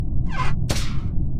Among Us Vent Out Sound Effect Free Download